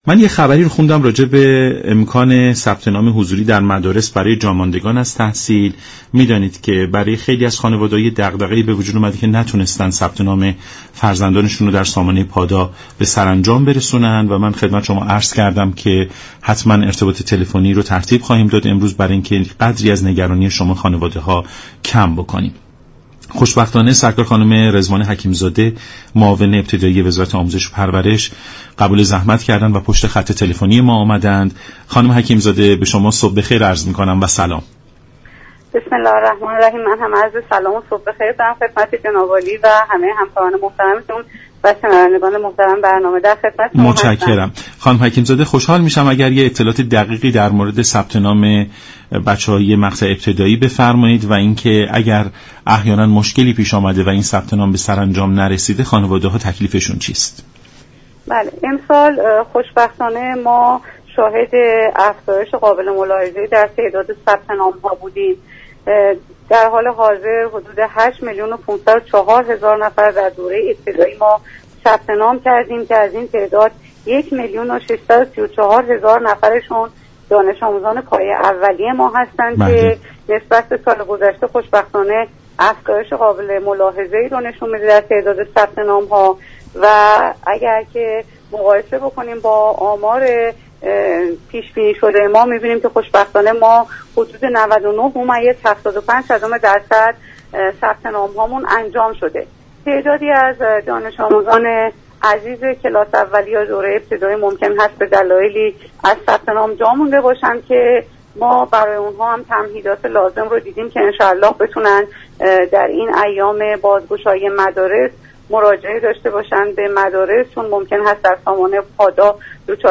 به گزارش شبكه رادیویی ایران، رضوان حكیم زاده معاون ابتدایی وزارت آموزش و پرورش در برنامه «سلام صبح بخیر» از افزایش قابل ملاحظه ای در تعداد ثبت نام ها خبر داد و گفت: در حال حاضر حدود 8 میلیون و 504 هزار نفر در دوران ابتدایی ثبت نام كرده اند كه از این تعداد، 1 میلیون و 634 هزار نفر پایه اول ابتدایی هستند.